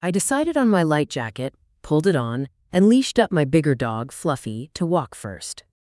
Text-to-Speech
Synthetic
Added Voice Lines